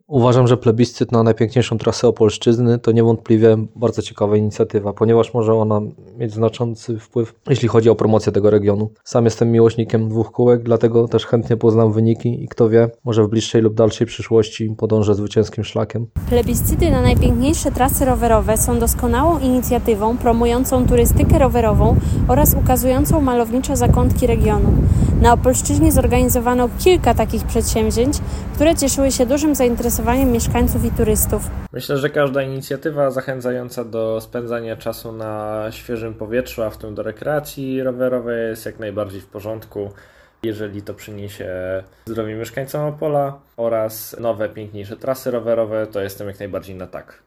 Zapytaliśmy się mieszkańców Opolszczyzny co o tym sądzą: